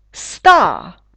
star [sta:]